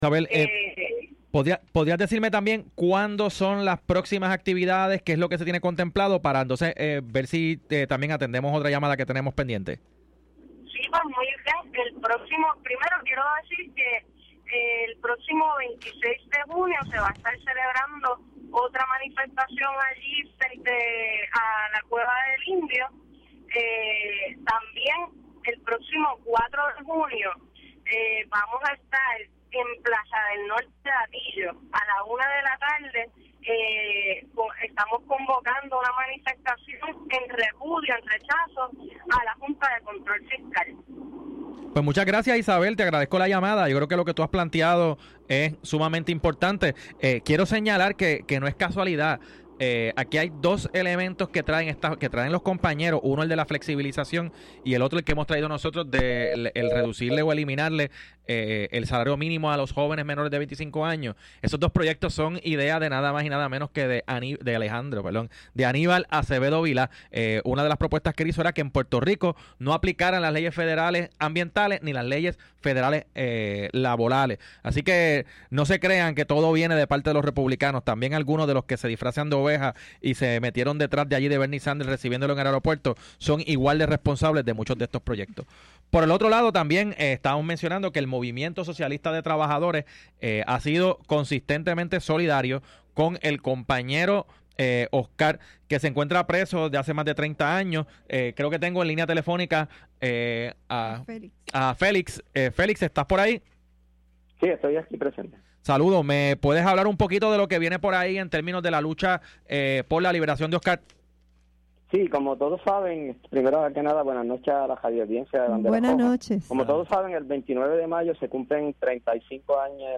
Transmitido el martes 24 de mayo 2016 de 6:00 a 7:oo de la noche por WIAC 740 AM